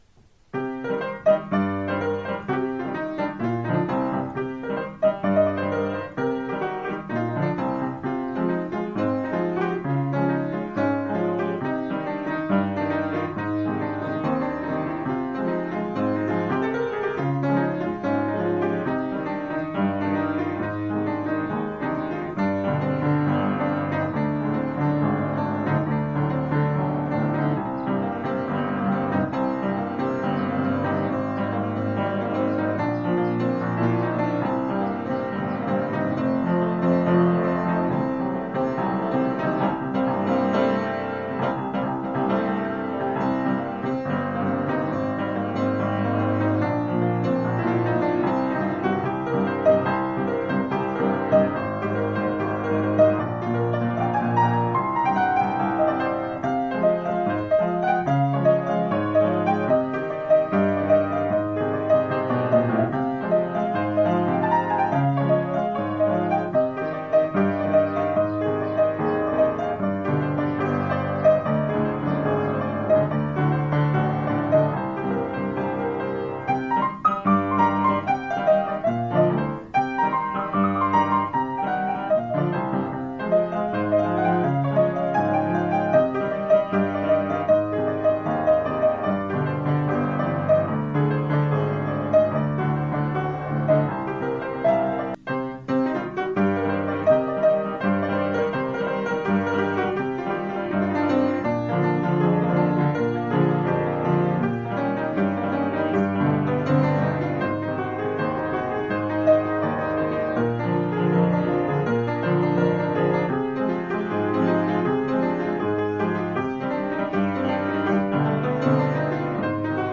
לחן והביצוע שלי.
הייתי גוזרת את הסוף עם כל הרישרושים והרעשים..